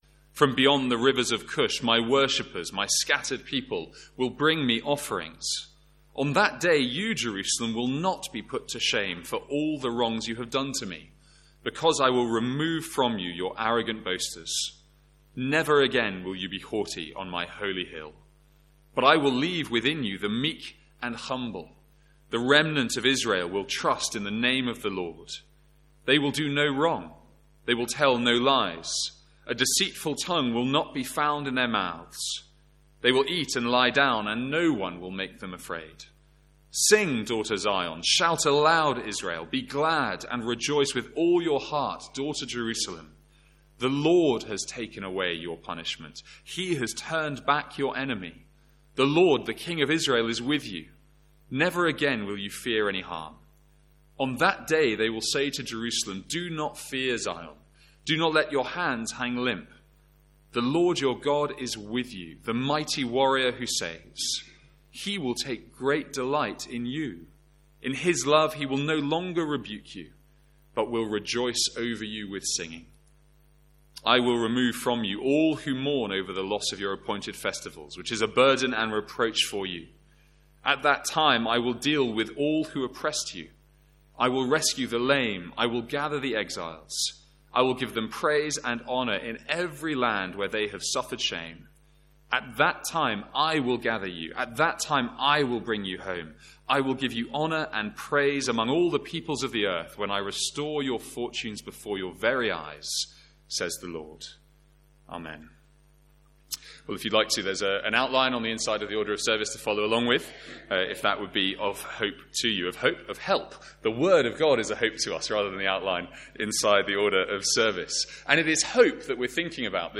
Sermons | St Andrews Free Church
v9 missed from the recording of the reading.